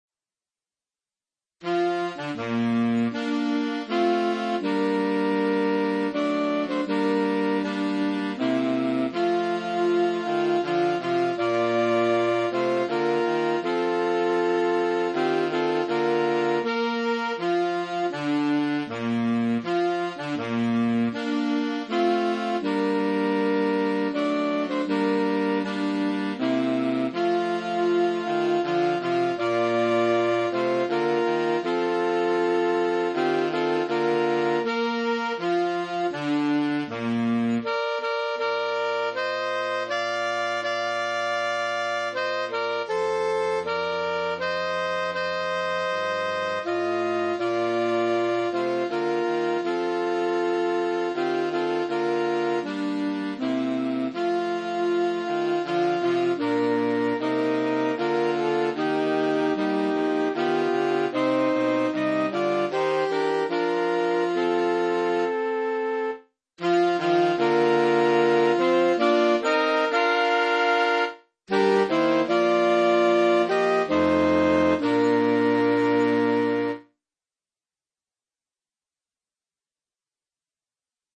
Voicing: Saxophone 4tet